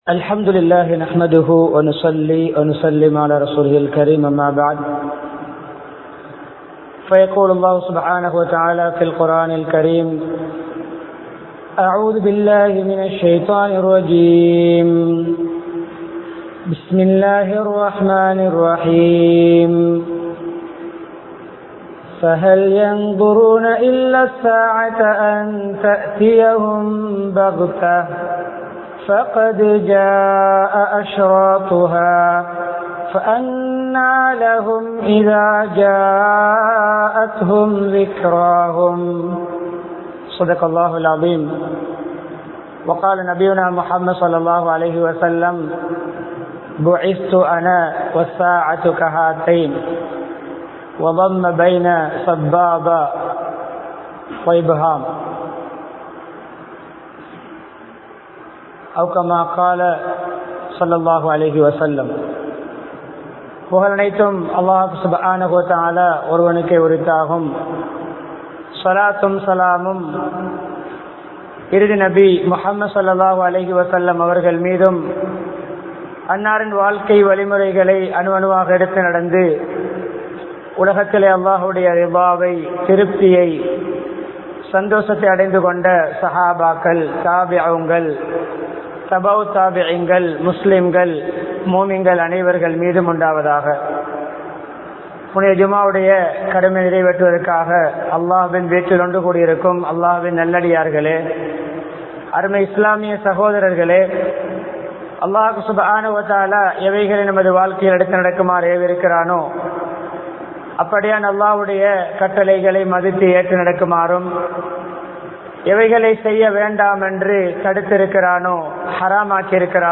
கியாமத் நாளின் அடையாளங்கள் | Audio Bayans | All Ceylon Muslim Youth Community | Addalaichenai
Mannar, Uppukkulam, Al Azhar Jumua Masjidh